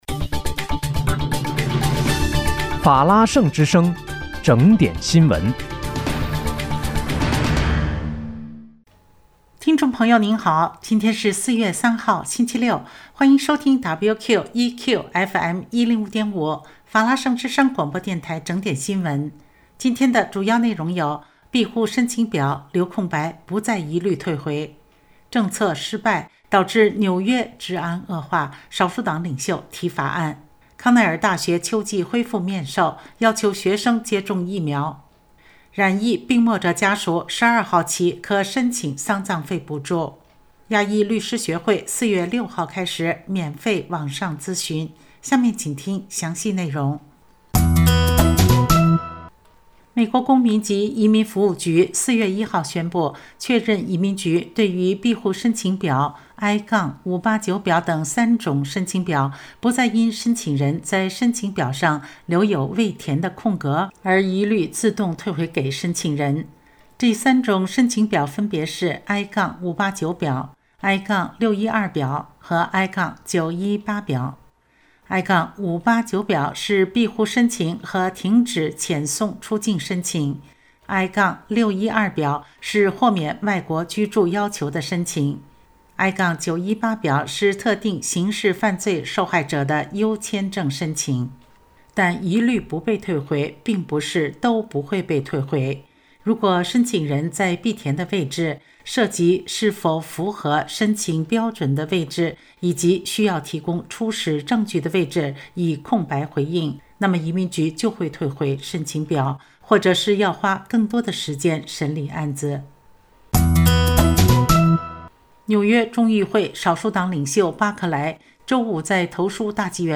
4月3日（星期六）纽约整点新闻